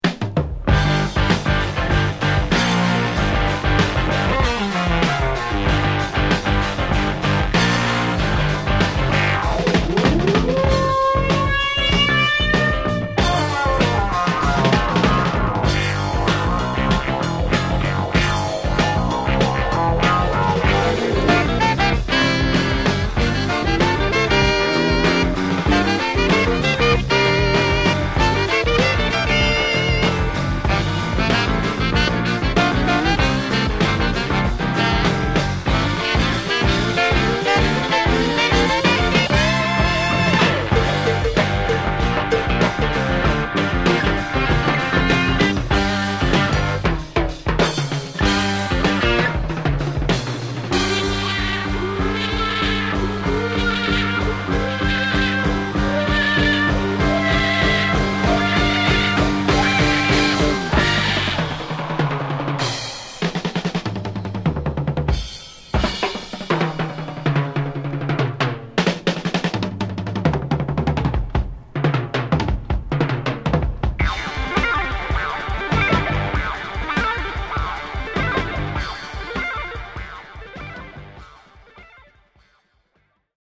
This hit single is specially edited from a 4:45 album track.